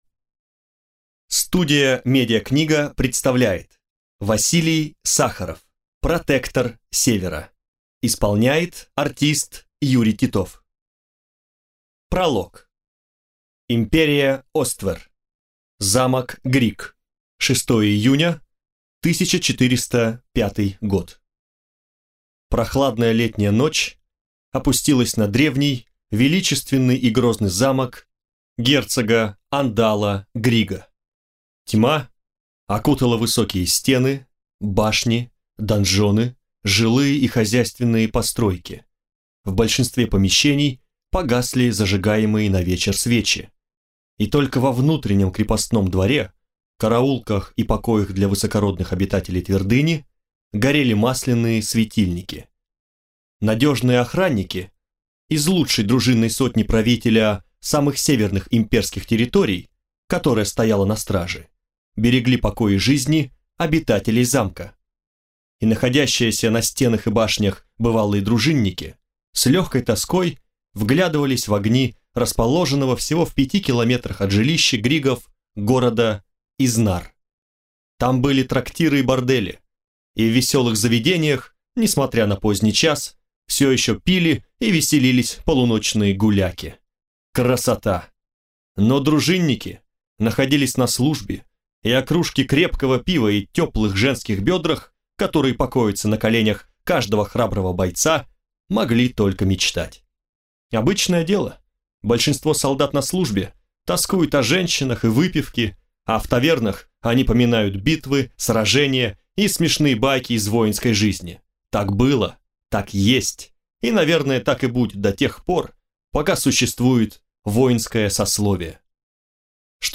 Аудиокнига Протектор севера | Библиотека аудиокниг